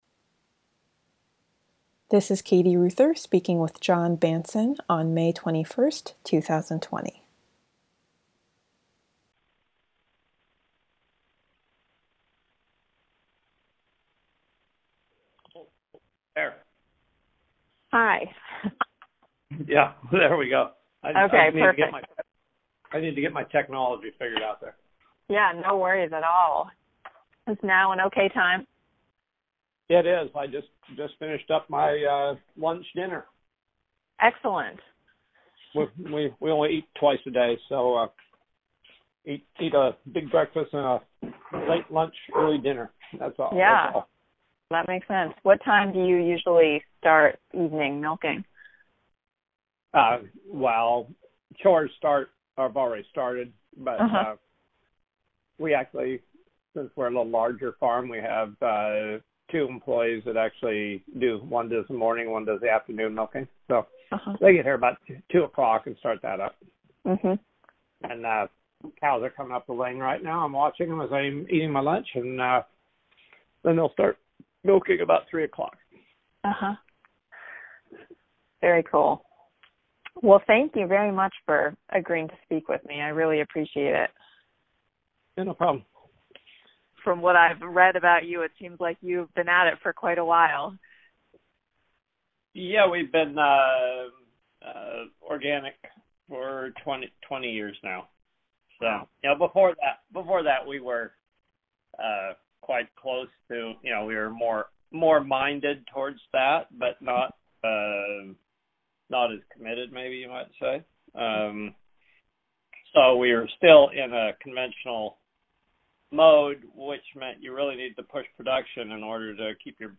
Remote interview